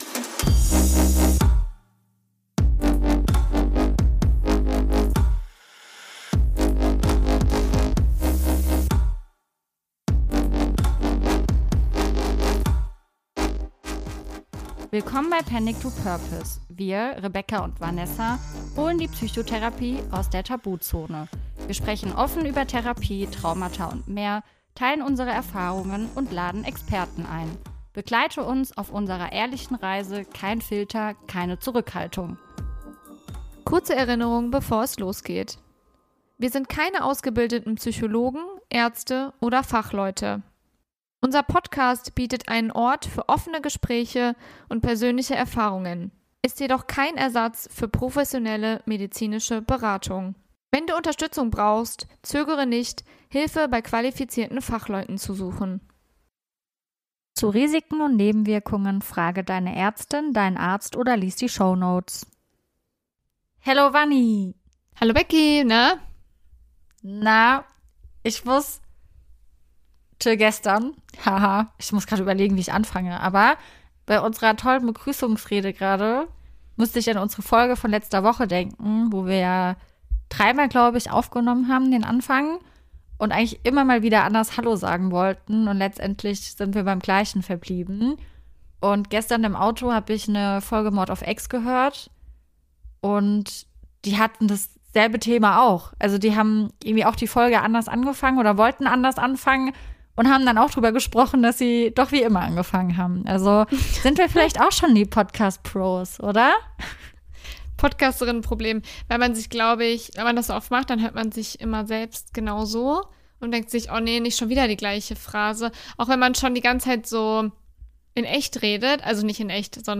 Diesmal gibt es wieder eine Laberfolge, in der wir frei Schnauze über Ober, Zwerge, das Gefühl, aus dem Körper gebeamt zu sein, und komische Situationen sprechen.